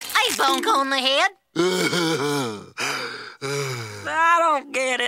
Puppet Pal Bonk - Geluidsknop
puppet_pal_bonk.mp3